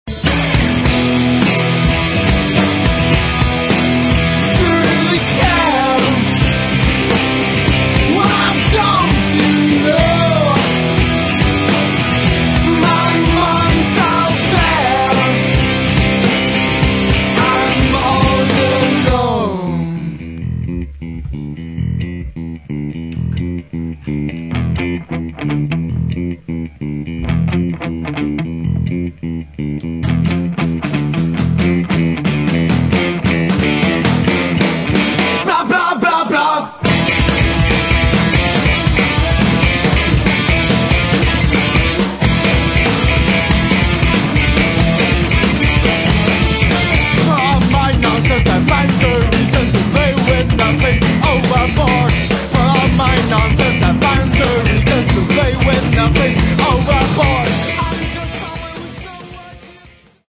Enominutni Lo-Fi MP3 izsečki za hitro predstavitev ...